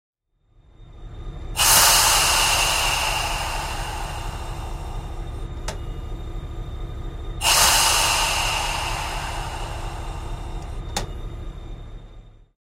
Паровоз отправляется